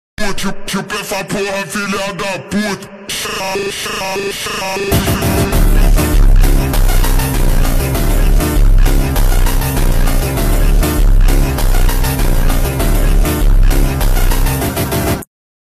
Hallam nebraska EF4 tornado of 2004
Srry for the crap quality!